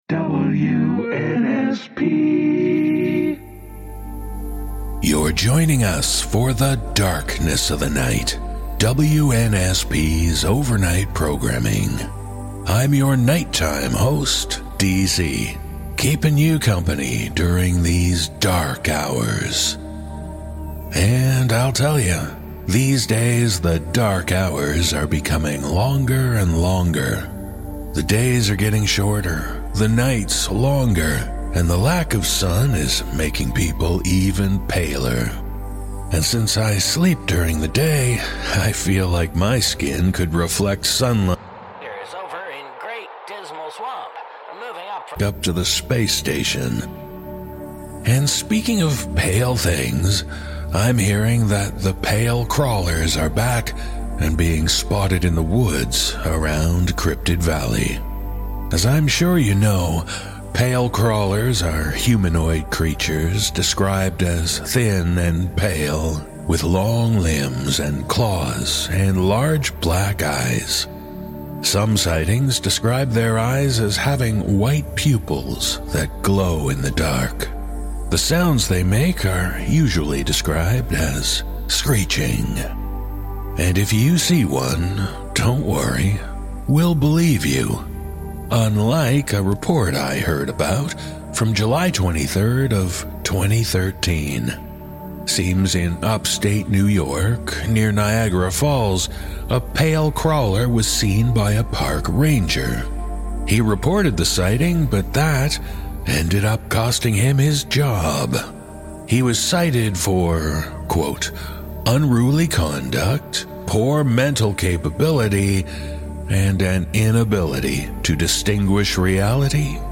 Musical score